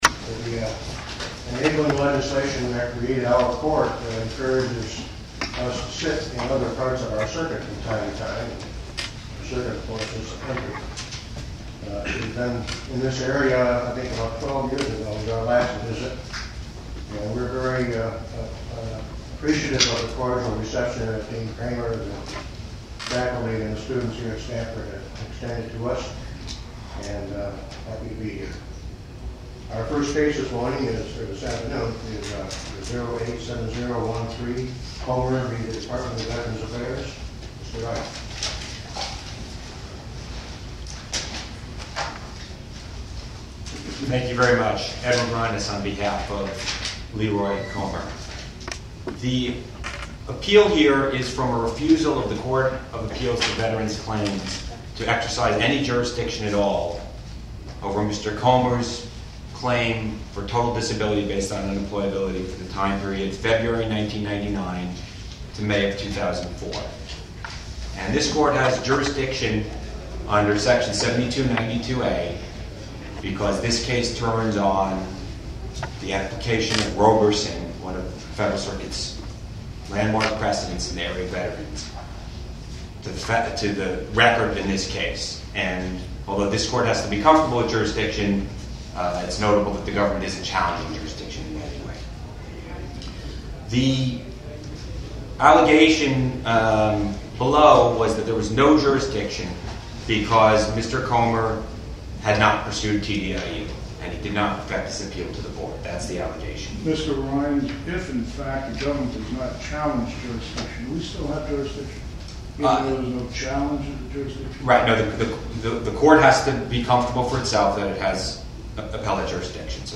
Oral argument audio posted